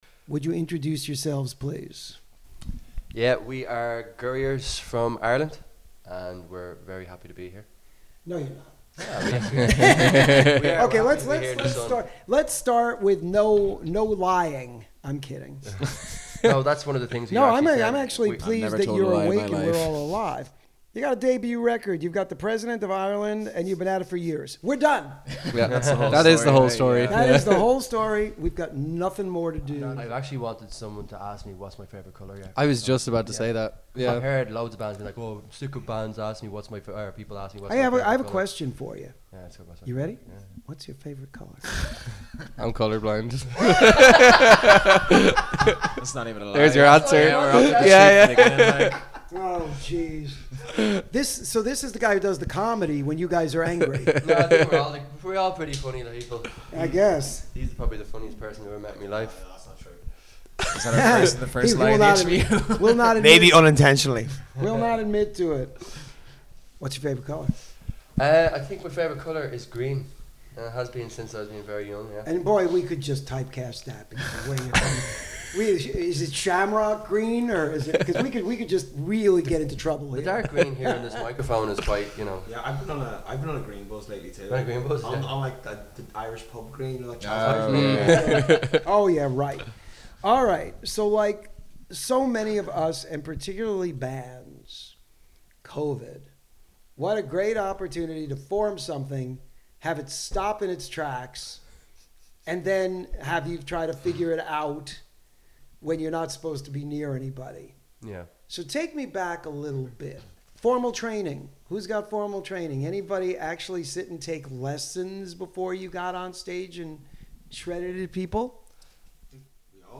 This Week's Interview (05/04/2025): Gurriers LISTEN TO THE INTERVIEW